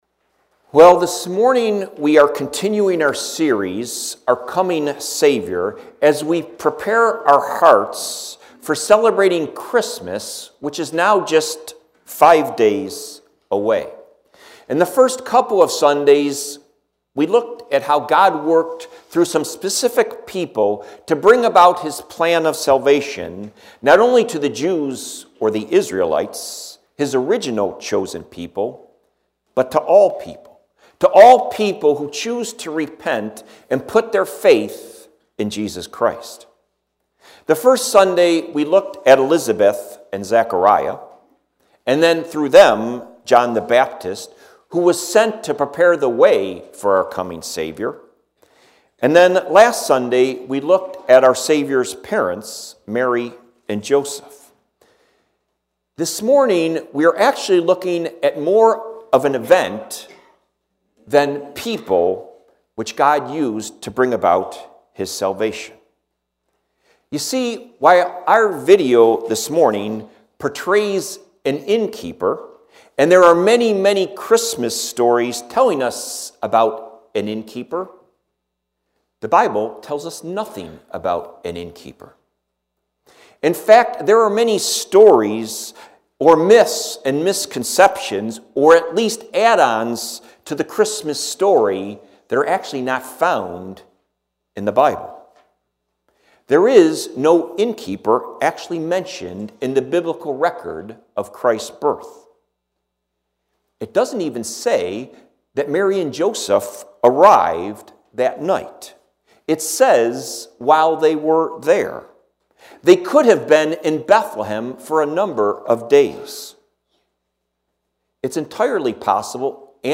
9:00 Service